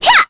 Selene-Vox_Attack4.wav